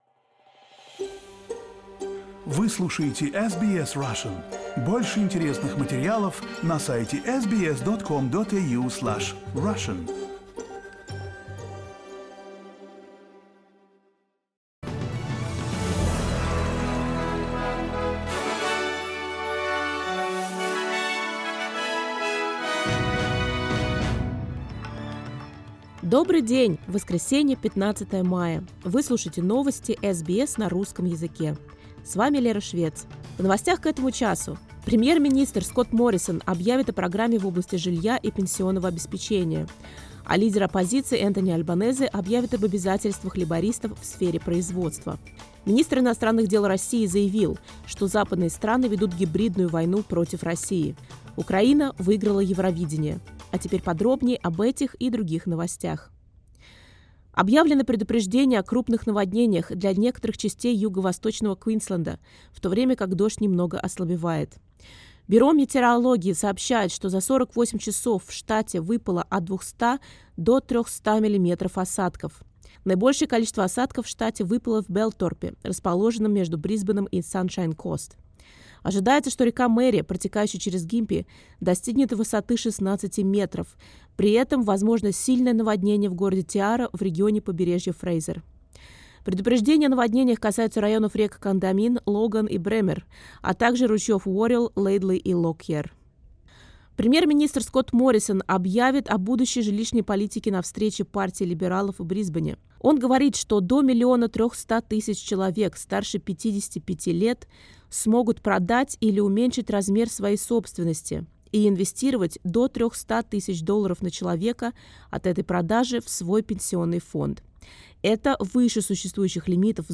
SBS news in Russian — 15.05.22